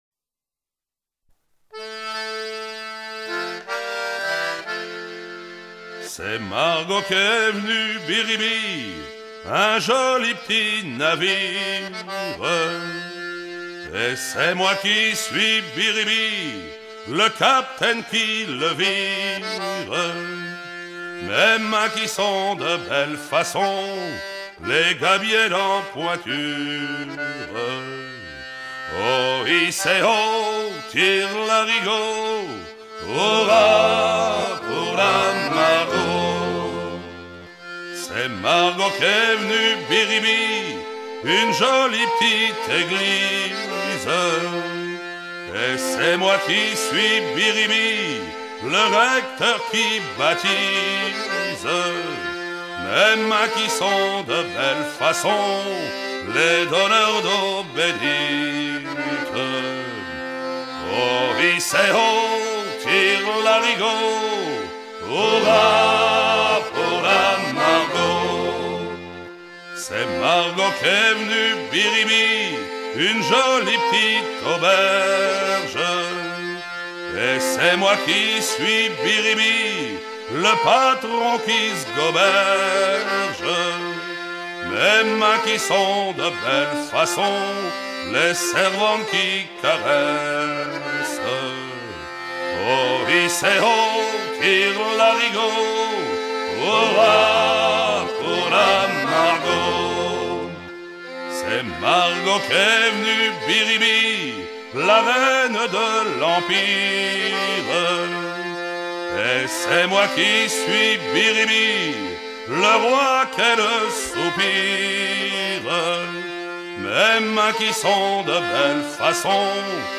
chant suivi d'un air de marche
Genre laisse